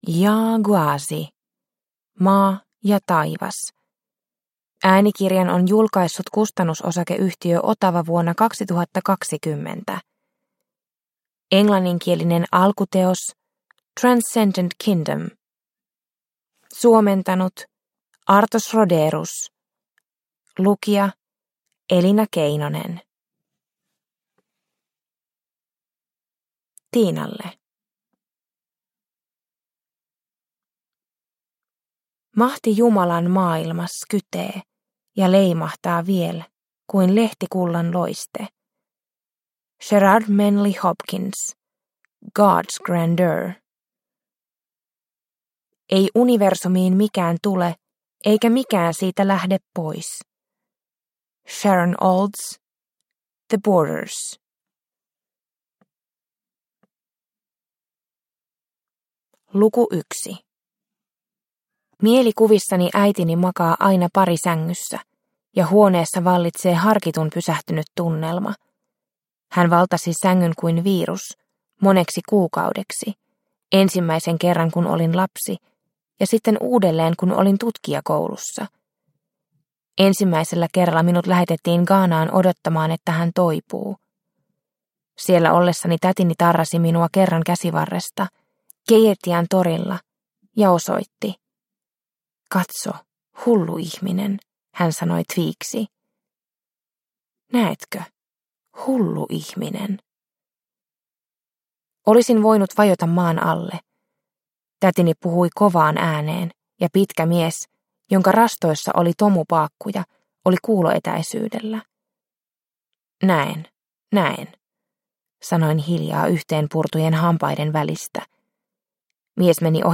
Maa ja taivas – Ljudbok – Laddas ner